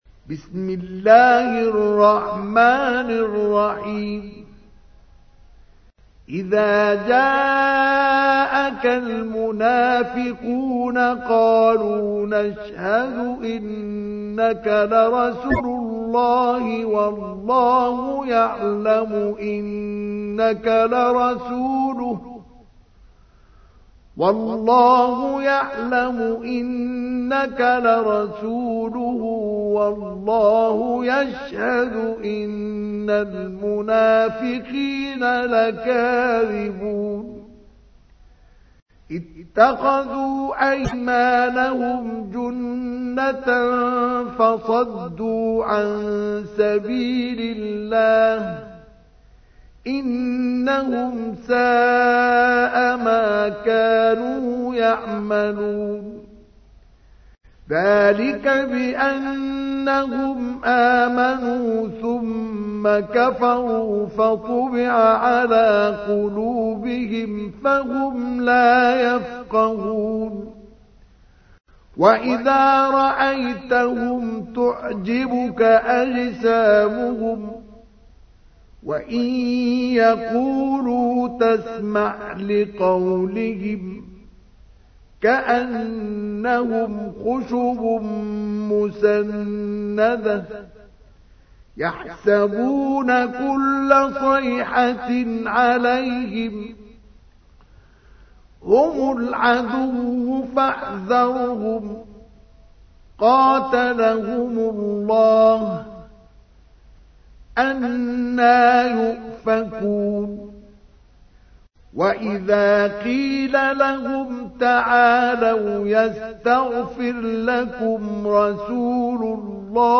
تحميل : 63. سورة المنافقون / القارئ مصطفى اسماعيل / القرآن الكريم / موقع يا حسين